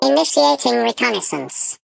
Sfx_tool_spypenguin_vo_deploy_cam_02.ogg